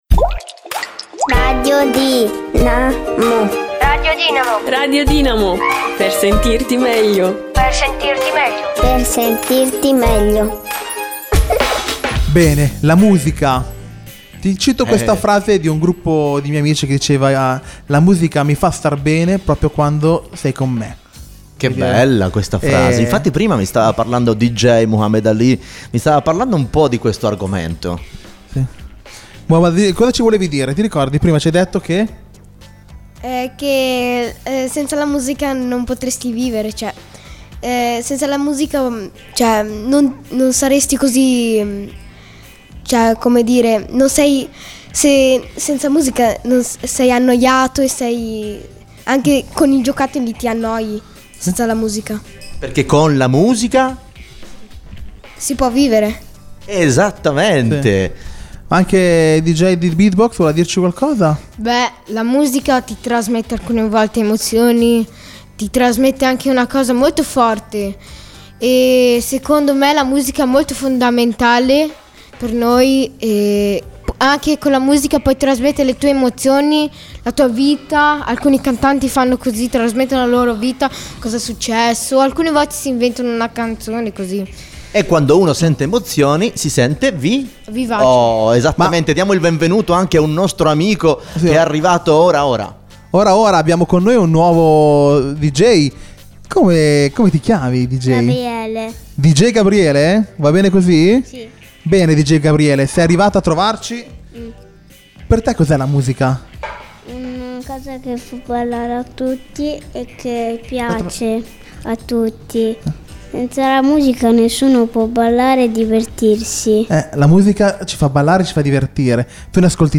LA DIRETTA!!